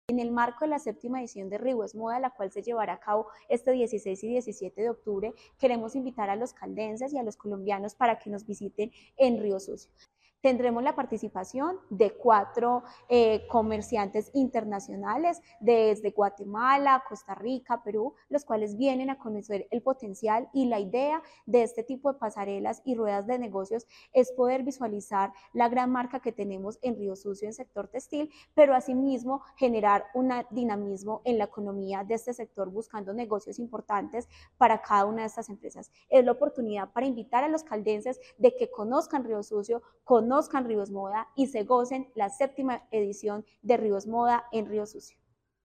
Tania Echeverry Rivera, secretaria de Desarrollo, Empleo e innovación de Caldas